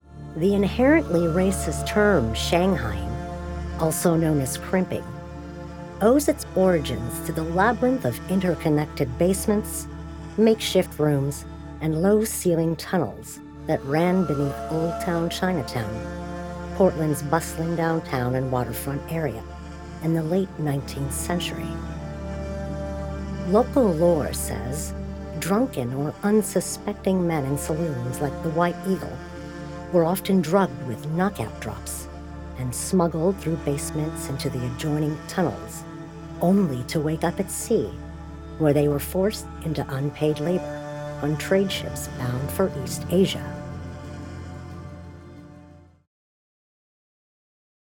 Documentary
I have a naturally rich, deep voice that exudes confidence while maintaining authenticity and relatability.